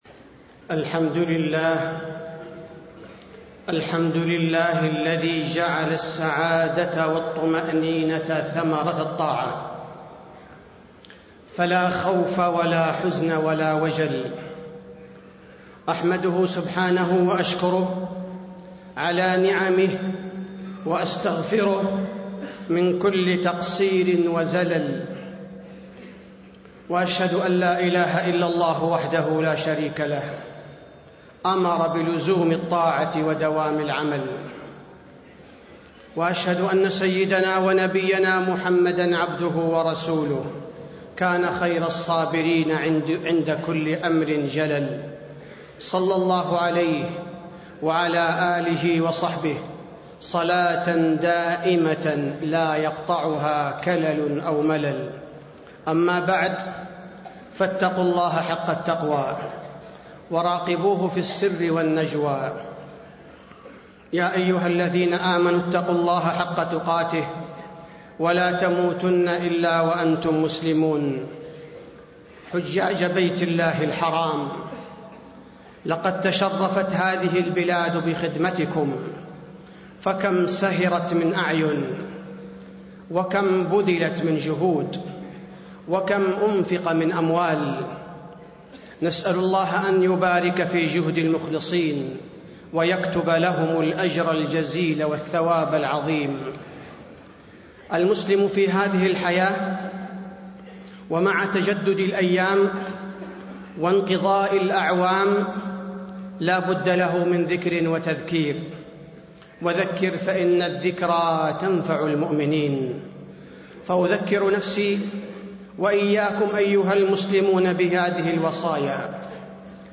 تاريخ النشر ٢٤ ذو الحجة ١٤٣٣ هـ المكان: المسجد النبوي الشيخ: فضيلة الشيخ عبدالباري الثبيتي فضيلة الشيخ عبدالباري الثبيتي وصايا إلى الحجاج